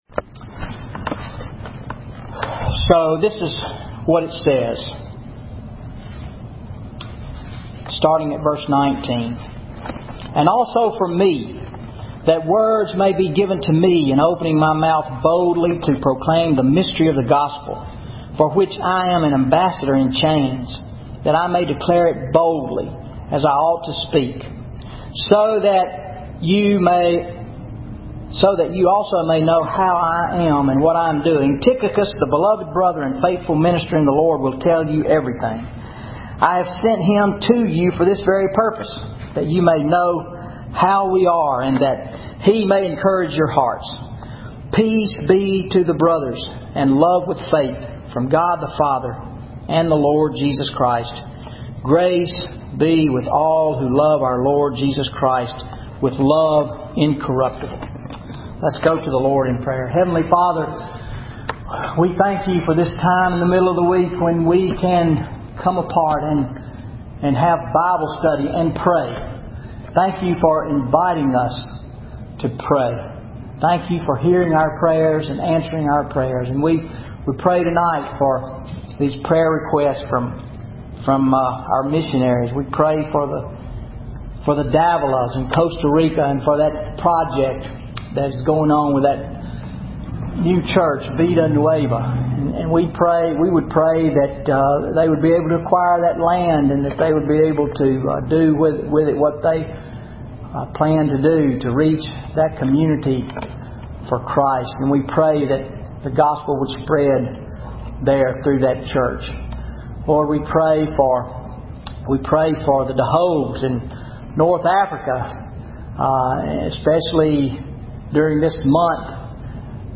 Wednesday Night Bible Study July 10, 2013 Ephesians 6:19-24